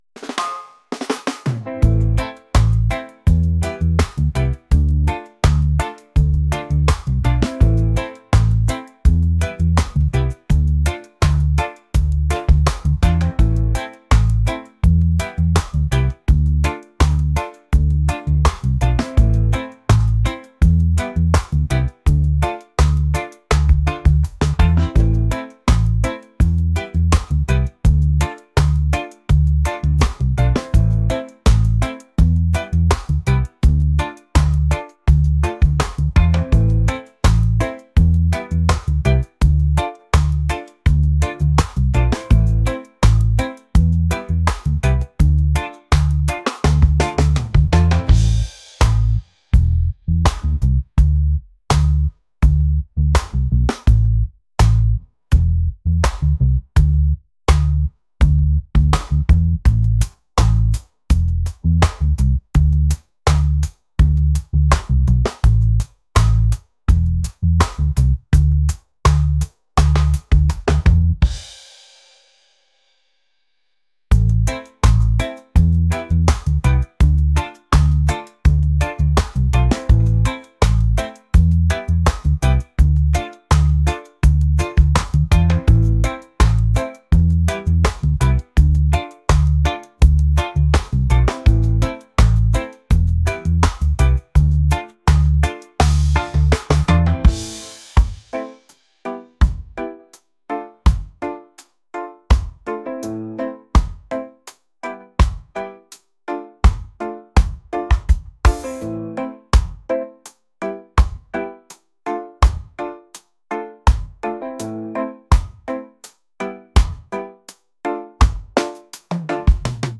reggae | lounge | lofi & chill beats